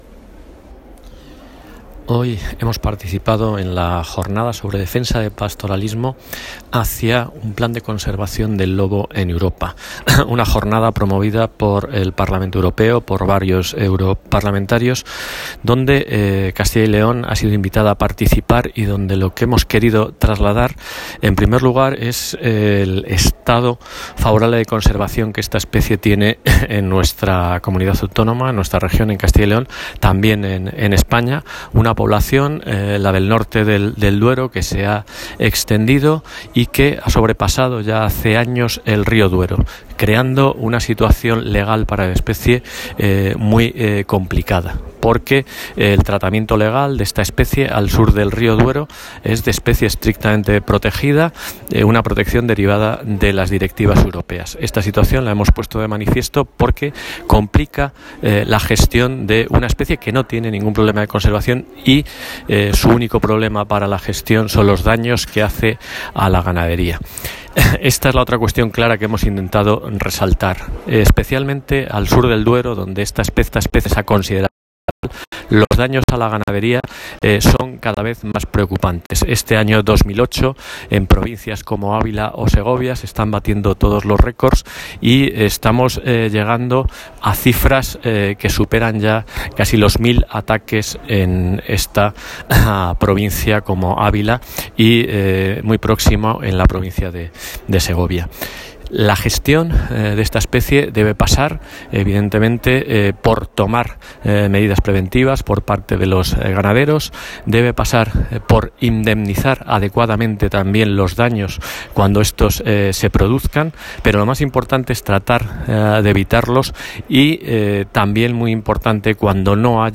Audio del director general del Medio Natural en la Conferencia en defensa del pastoreo en el Parlamento Europeo | Comunicación | Junta de Castilla y León
El director general del Medio Natural, José Ángel Arranz, ha acudido hoy como ponente a la Conferencia que se celebra en el Parlamento Europeo en defensa del pastoreo, la gestión del lobo en los problemas que causa en la ganadería.